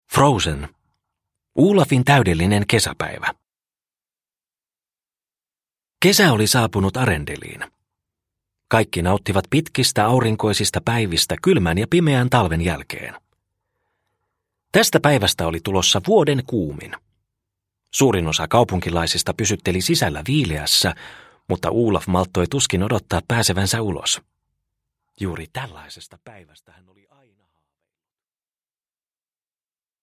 Frozen. Olafin täydellinen kesäpäivä – Ljudbok – Laddas ner